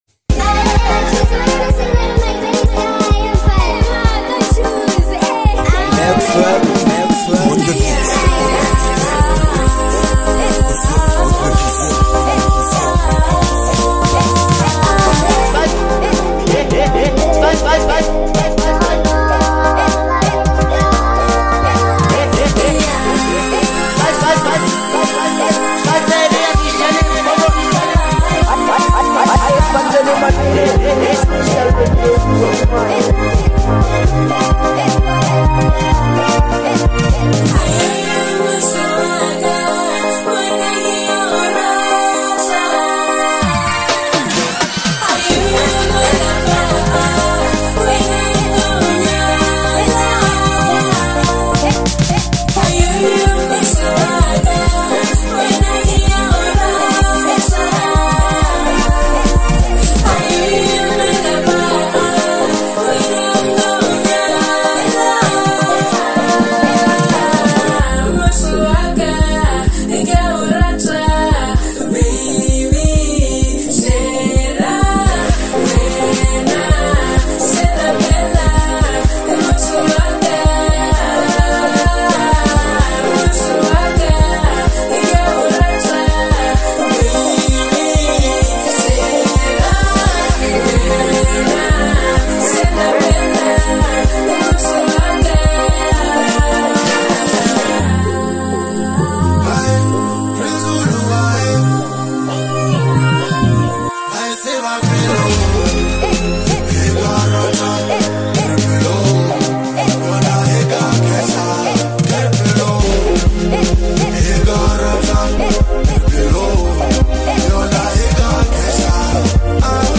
a smooth and emotionally rich love anthem
the song creates a warm and inviting atmosphere